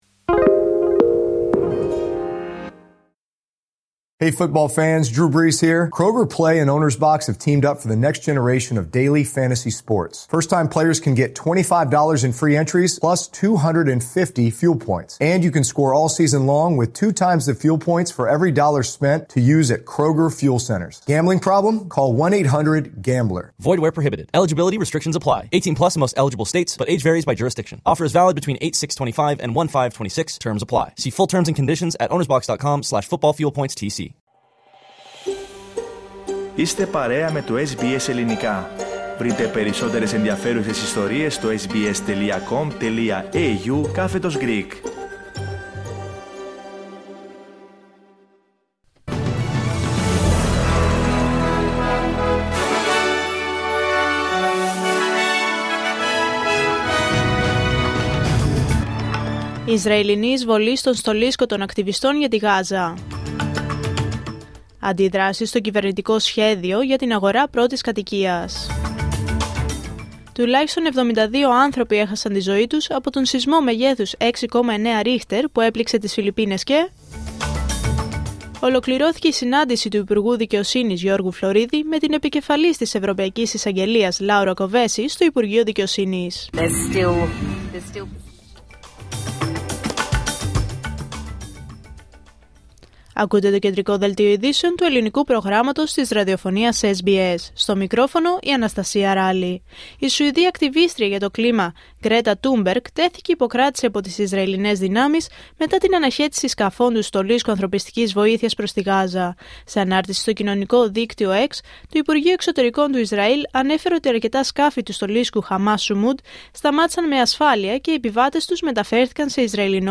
Δελτίο Ειδήσεων Πέμπτη 2 Οκτωβρίου 2025